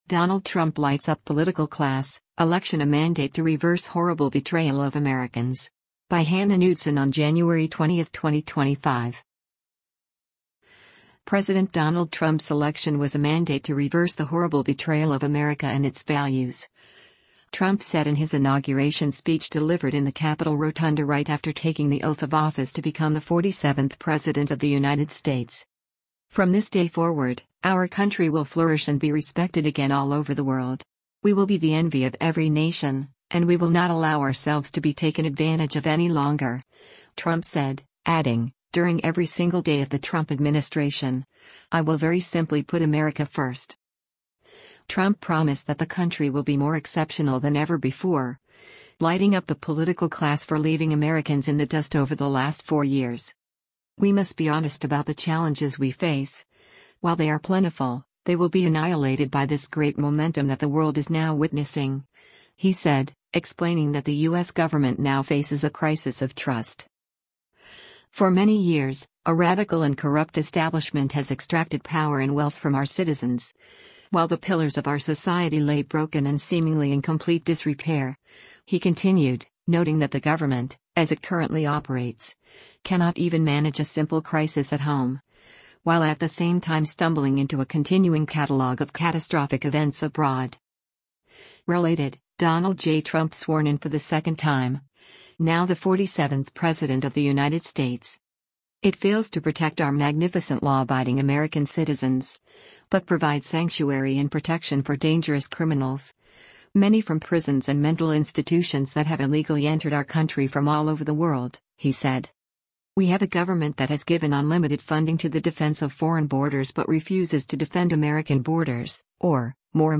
Attendees cheer as President Donald Trump speaks after taking the oath of office during th
President Donald Trump’s election was a mandate to reverse the “horrible betrayal” of America and its values, Trump said in his inauguration speech delivered in the Capitol Rotunda right after taking the oath of office to become the 47th President of the United States.